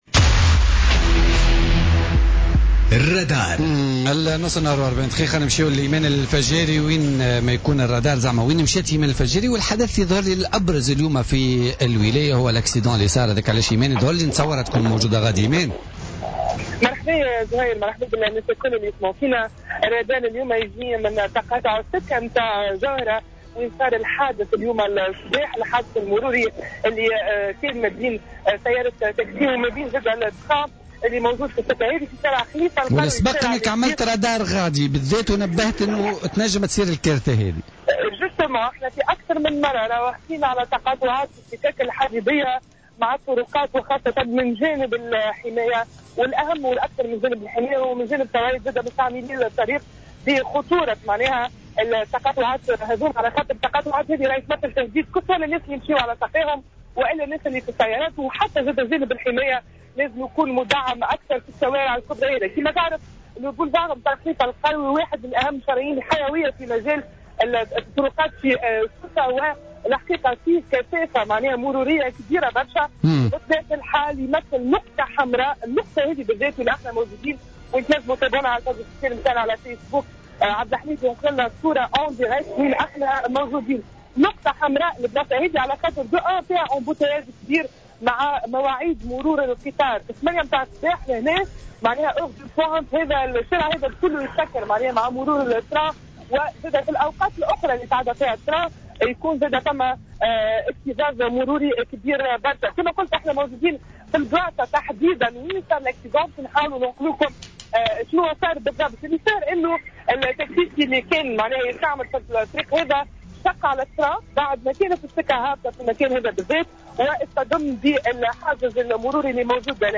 تنقّل فريق "الرادار" إلى تقاطع سكة القطار جوهرة بسوسة، أين جد حادث المرور صباح اليوم الاثنين 14 أوت 2017 بين قطار وسيارة أجرة.